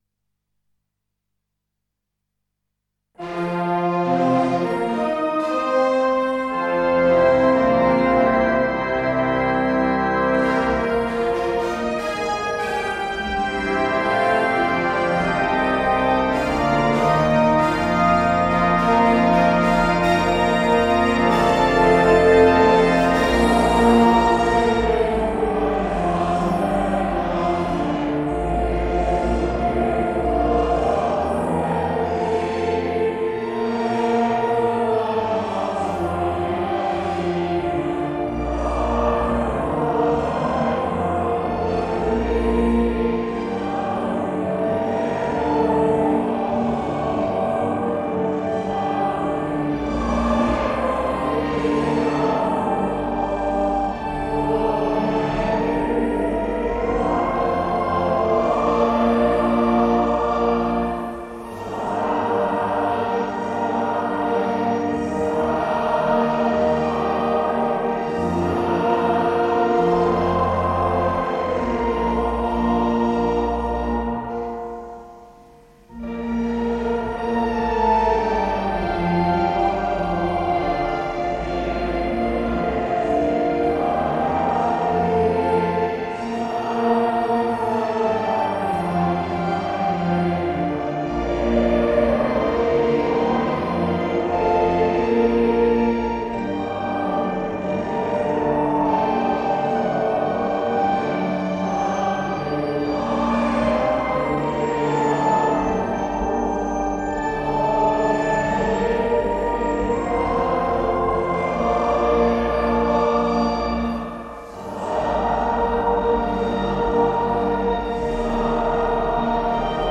Event: Church in Wales Conference
Location: University Hall Aberystwyth
Details: Recorded on a WH Smiths bargain 49p audio cassette – hence the scintillating hi-fi.